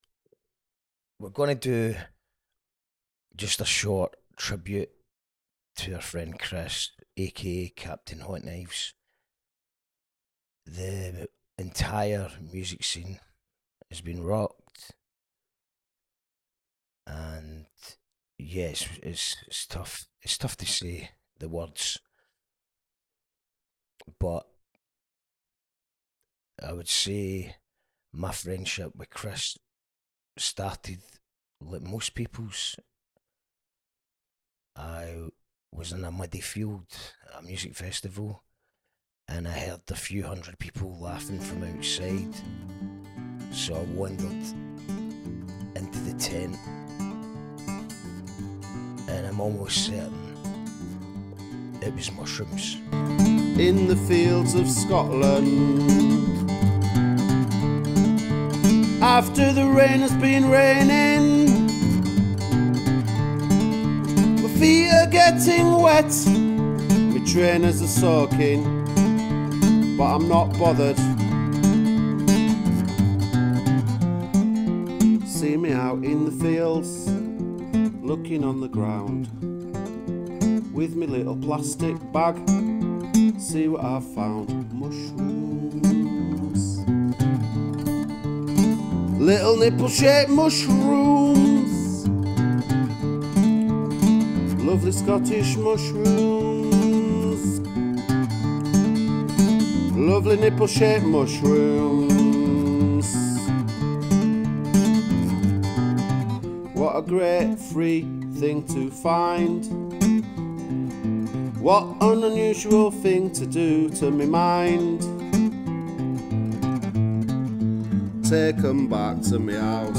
We interupt our scheduled programming to pay tribute to a maverick musician.